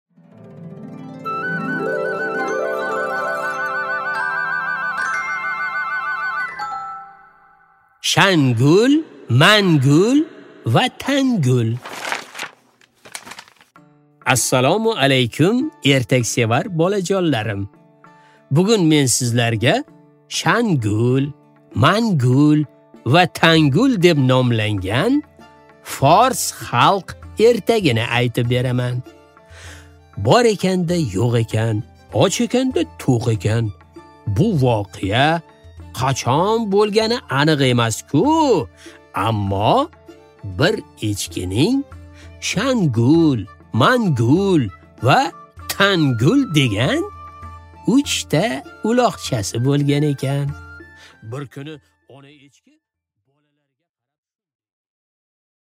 Аудиокнига Shangul, mangul va tangul